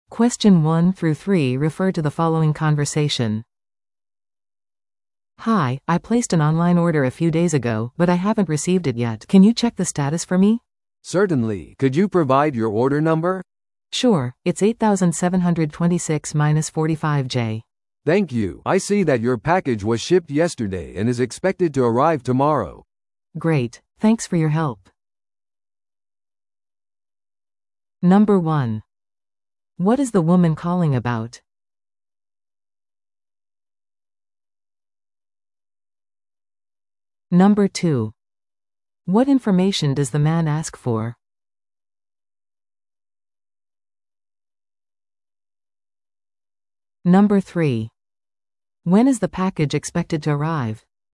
No.1. What is the woman calling about?
No.2. What information does the man ask for?